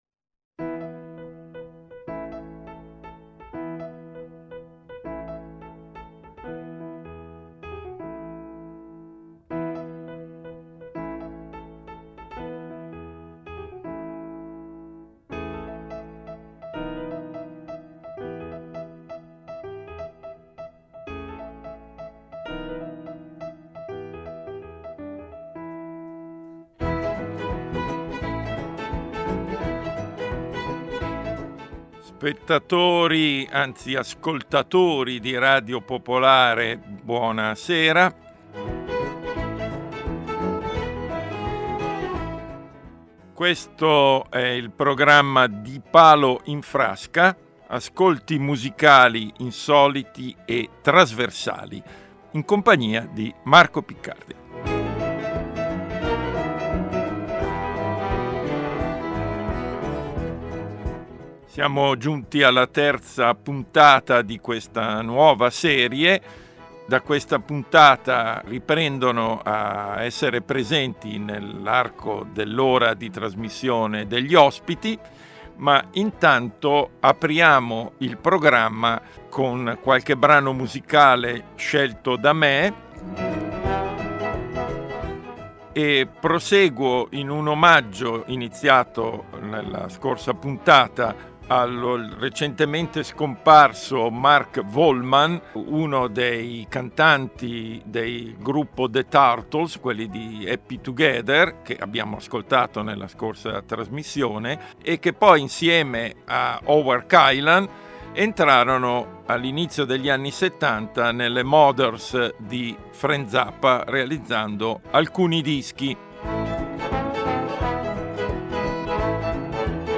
Ospite in studio: Enrico Intra.